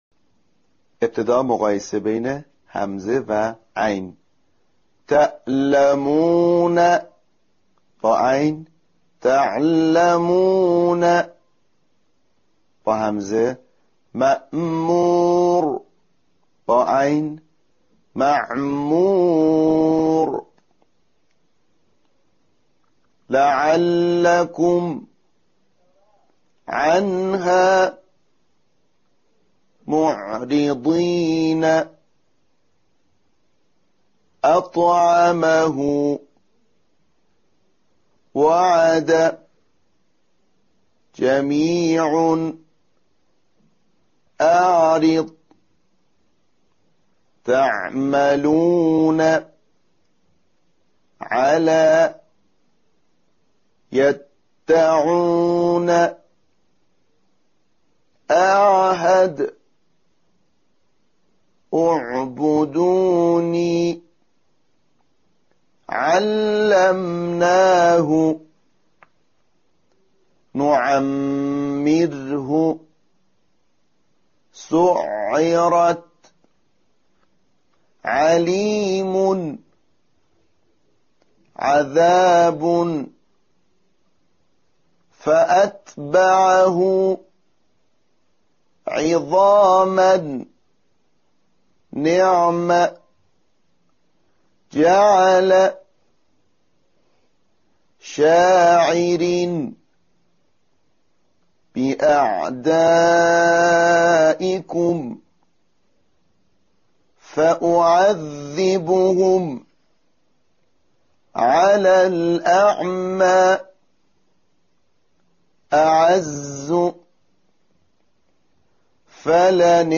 این حرف از حروف حلقی است و مانند حرف «ح» در وسط حلق ساخته می شود.
تمرین عملی_مرحله ۲